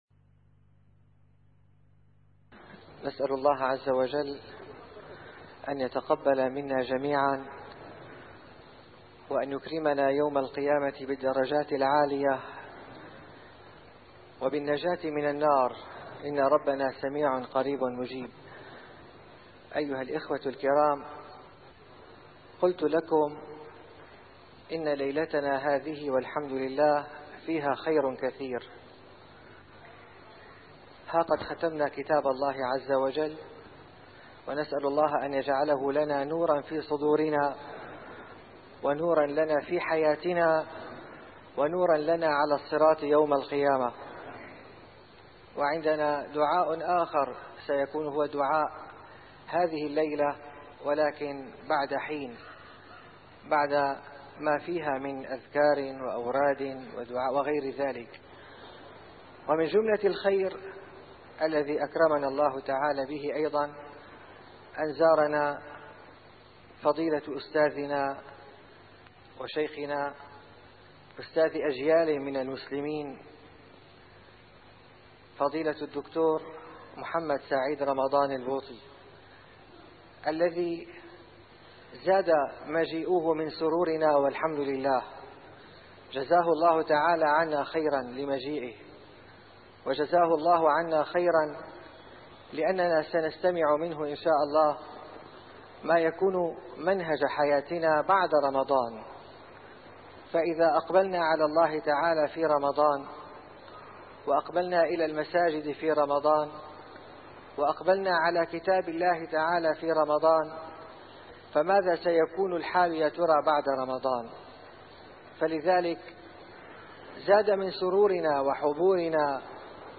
A MARTYR SCHOLAR: IMAM MUHAMMAD SAEED RAMADAN AL-BOUTI - الدروس العلمية - محاضرات متفرقة في مناسبات مختلفة - محاضرة العلامة الشهيد الإحسان والإيمان